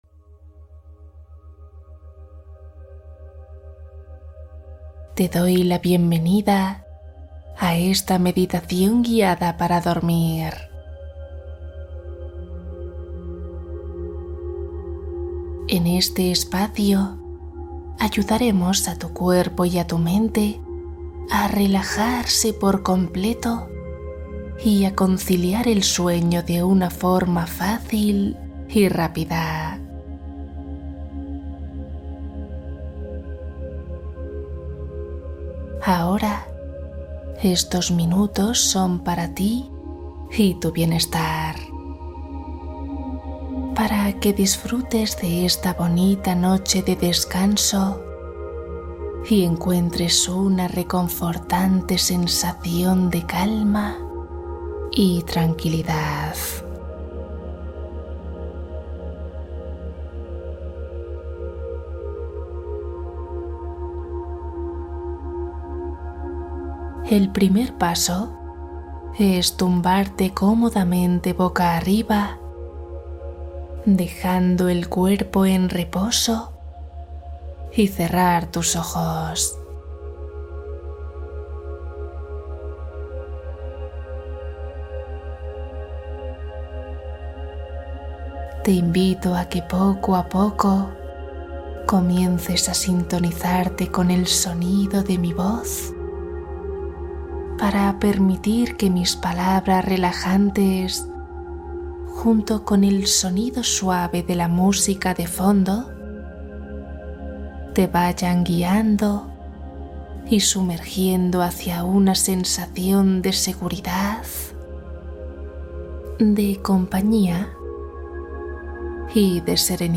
Adiós al insomnio Meditación guiada para dormir rápido y profundo